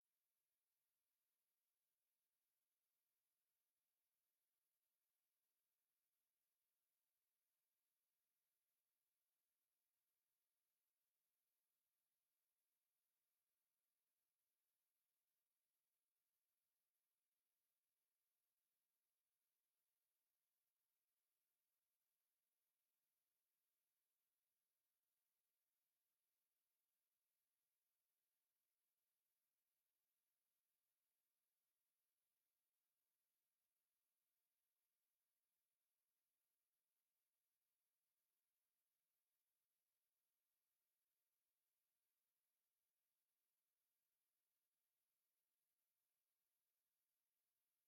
Raadscommissie 17 september 2024 20:00:00, Gemeente Renkum
Raadzaal